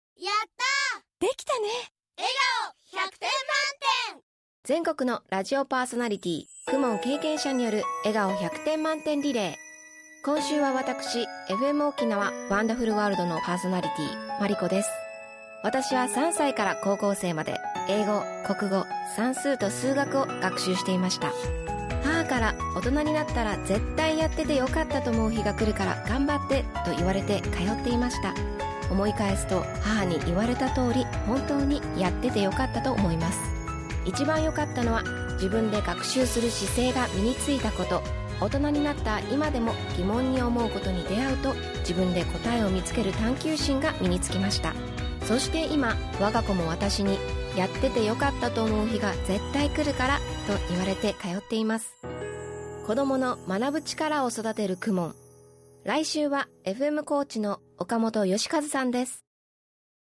「子どもの頃、KUMONやってました！」 「今、子どもが通っています！」･･･という全国のパーソナリティのリアルな声をお届けします。